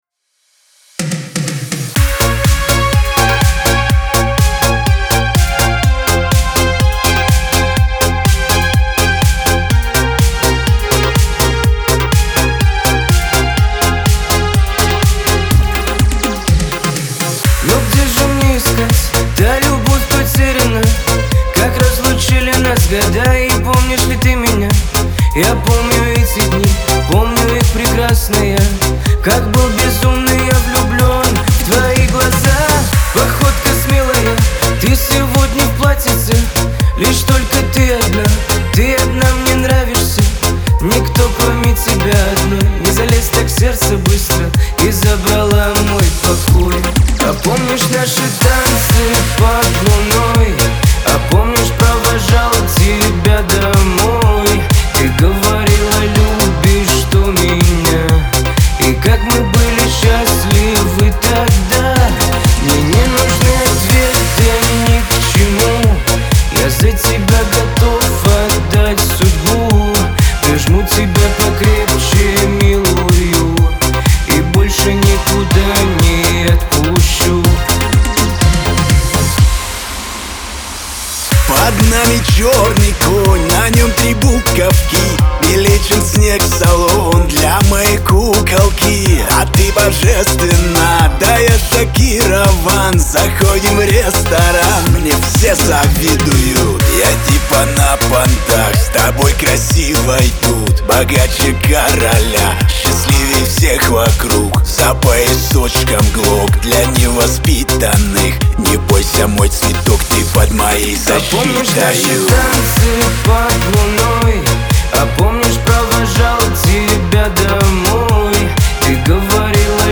эстрада
ХАУС-РЭП
дуэт